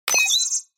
دانلود آهنگ کلیک 11 از افکت صوتی اشیاء
جلوه های صوتی
دانلود صدای کلیک 11 از ساعد نیوز با لینک مستقیم و کیفیت بالا